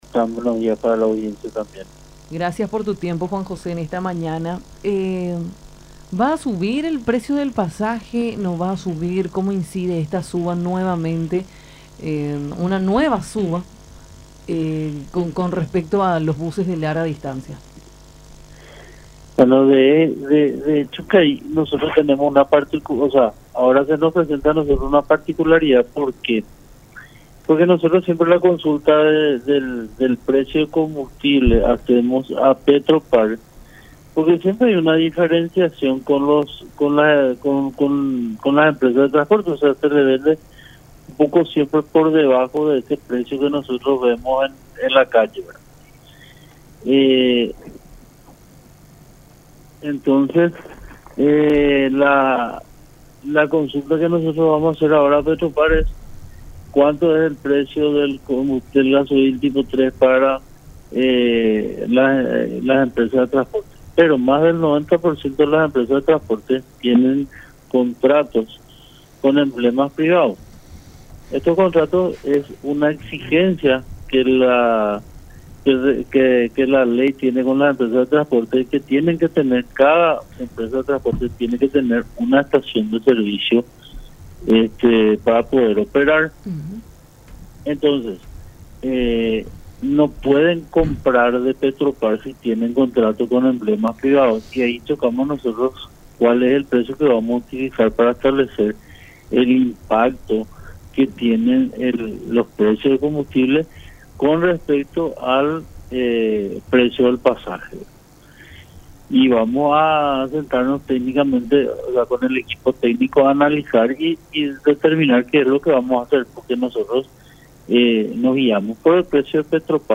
“Yo creo que los tiempos ya no dan para que en Semana Santa tengamos un aumento de pasajes”, reforzó en diálogo con Nuestra Mañana por La Unión.